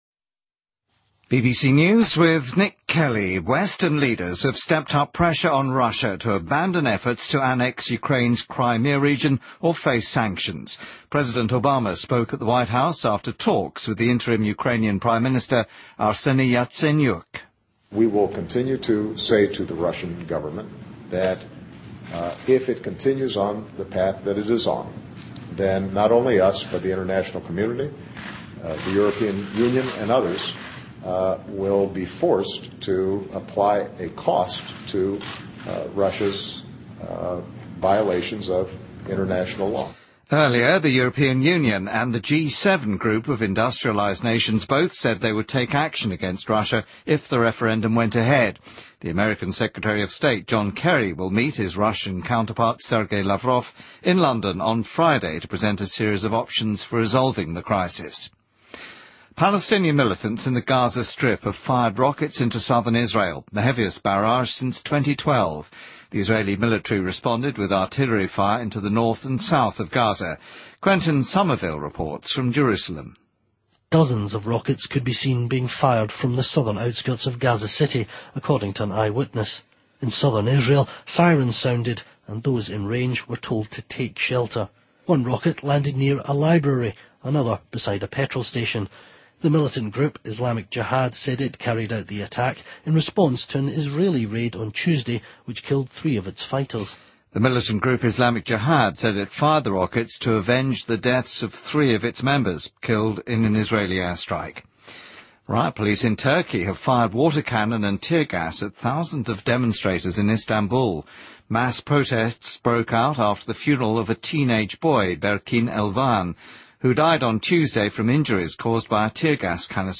BBC news,2014-03-13